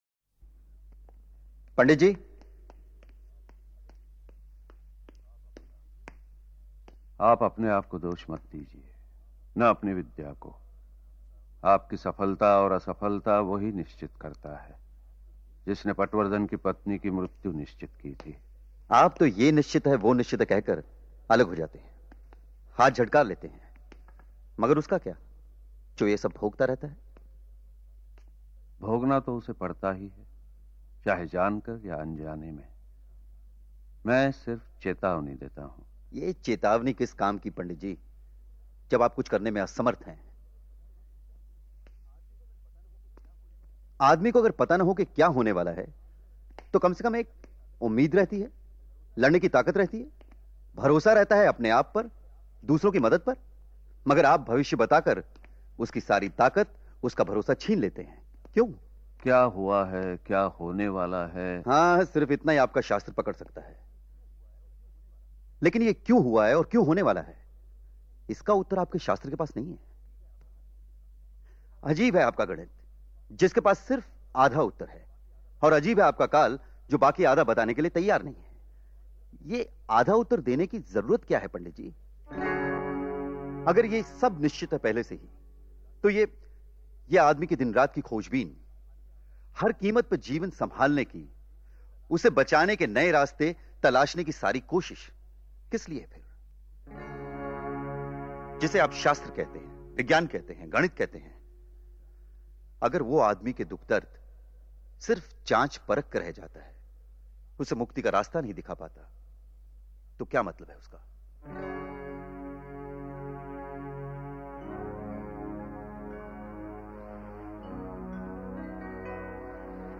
[Artist: Dialogue ]